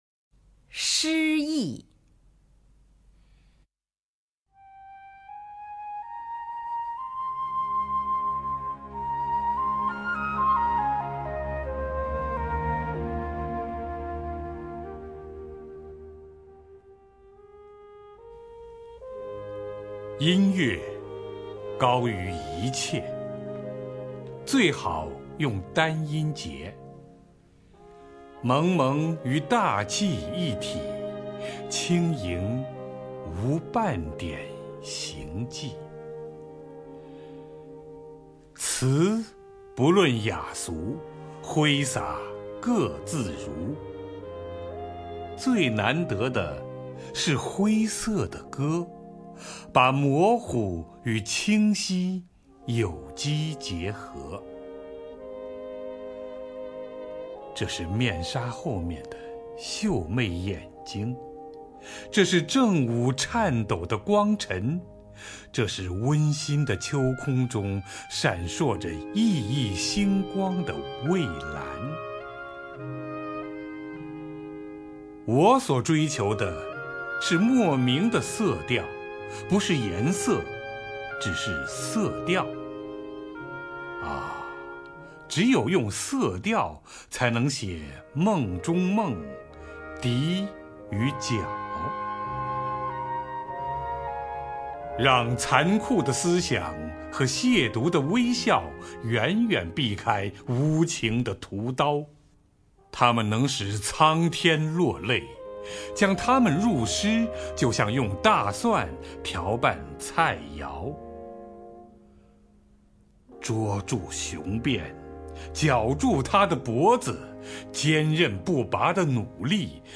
乔榛朗诵：《诗艺（节选）》(（法）保尔·魏尔伦)